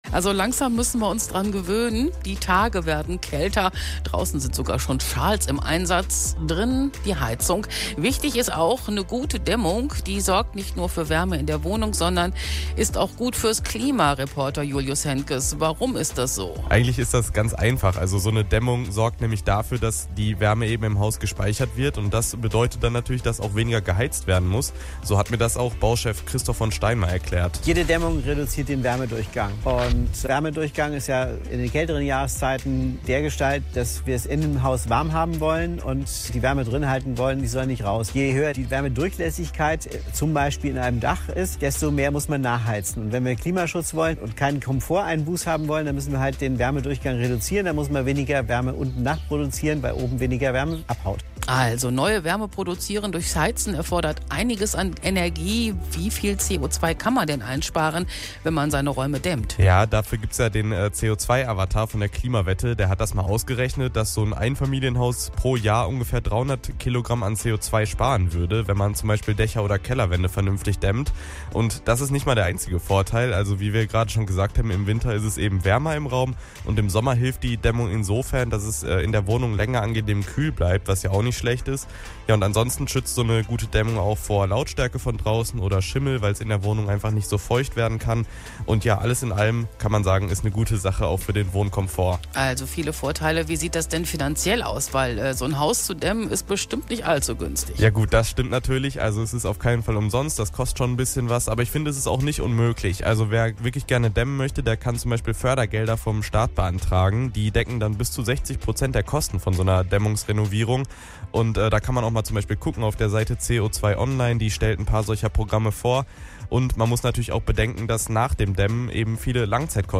Talk zur Klimawette: Dämmen - Radio Hagen